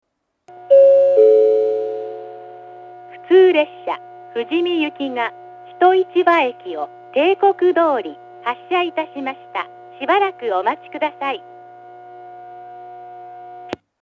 発車ベルはありませんが、接近放送があります。
２番線上り一日市場駅発車案内放送 普通富士見行の放送です。